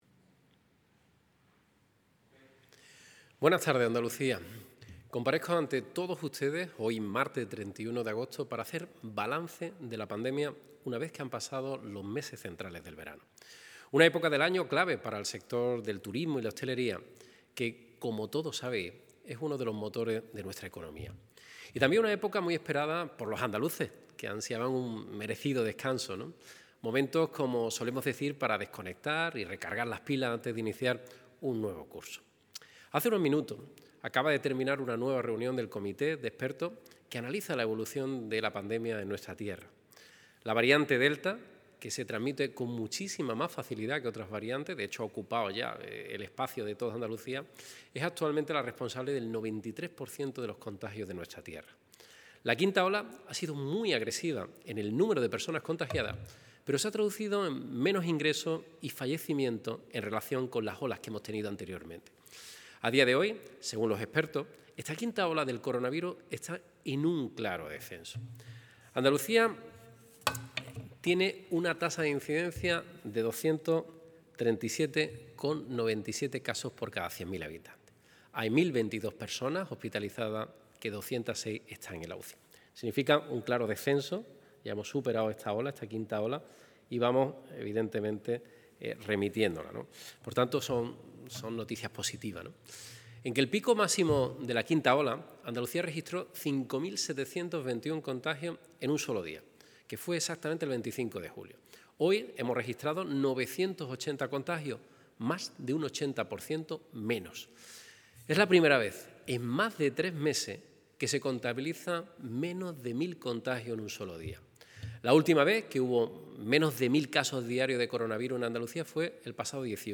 Estas son algunas de las medidas más destacadas que el presidente de la Junta de Andalucía, Juanma Moreno, ha explicado este martes en una comparecencia informativa a la conclusión de la reunión del comité de expertos y cuya entrada en vigor el presidente de la Junta ha cifrado en la medianoche de este miércoles al jueves, del 1 al 2 de septiembre.